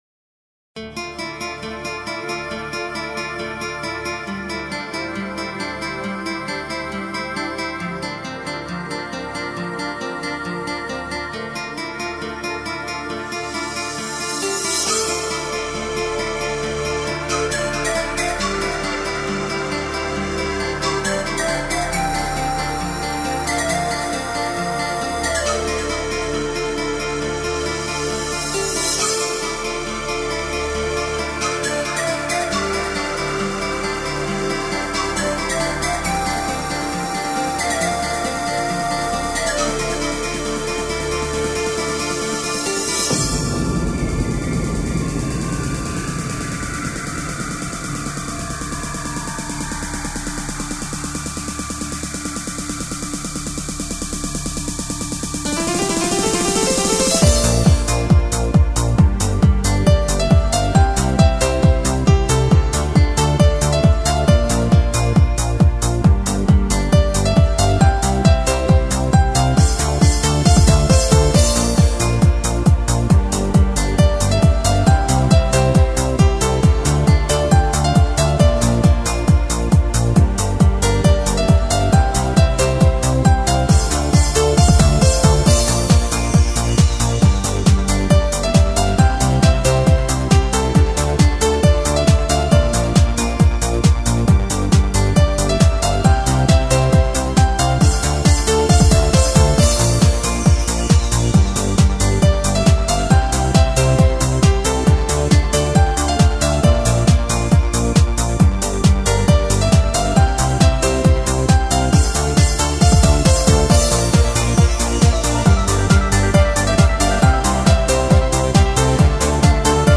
Энергия - эмоции - чувства - движение...
Жанр:Electronic